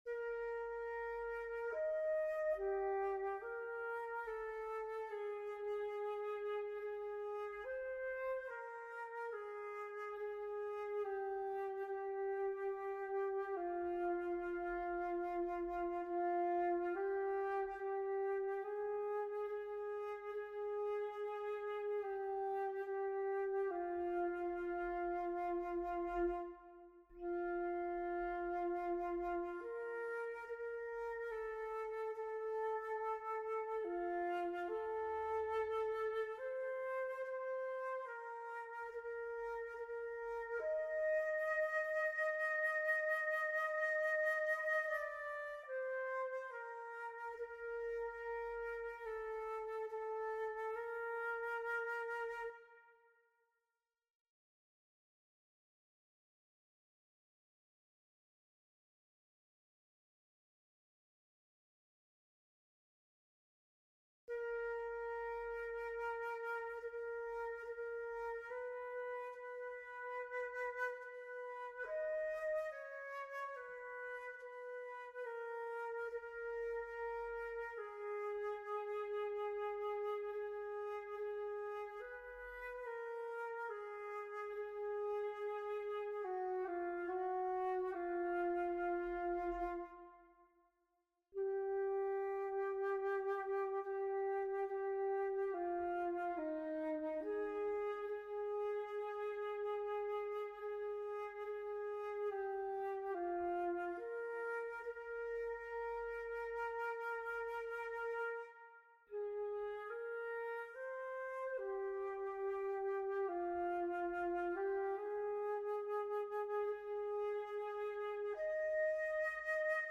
sacred motet
This arrangement is for solo flute.